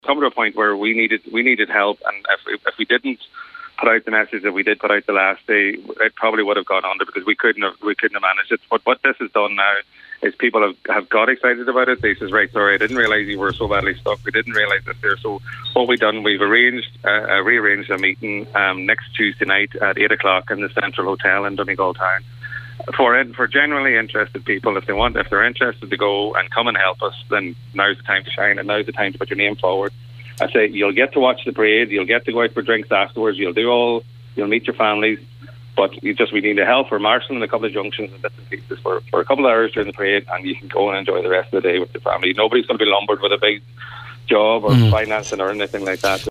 spoke on today’s Nine til Noon Show and he’s hopeful a resolution can be found